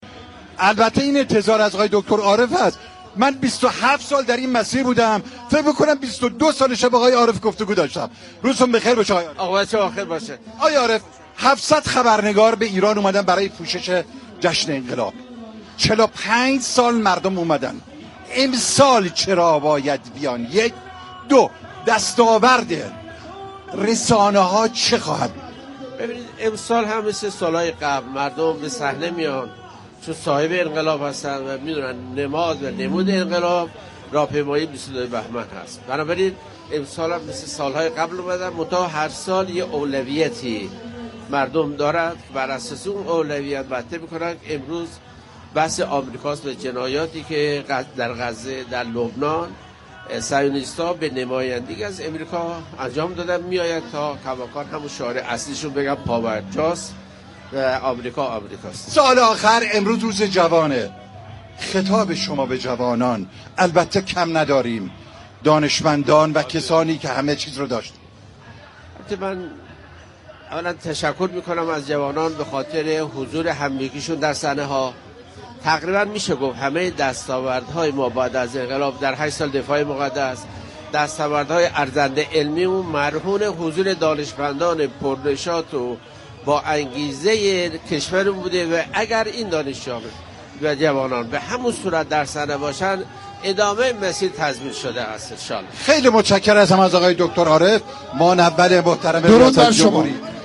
به گزارش پایگاه اطلاع رسانی رادیو تهران، محمدرضا عارف معاون اول رئیس ‌جمهور در گفت و گو با «اینجا تهران است» ویژه برنامه چهل و ششمین سالروز پیروزی انقلاب اسلامی اظهار داشت: امسال هم همانند سالهای قبل مردم با حضور در راهپیمایی 22 بهمن به صحنه آمدند؛ چون صاحب انقلاب هستند و می‌دانند نمود انقلاب حضور در راهپیمایی 22 بهمن است.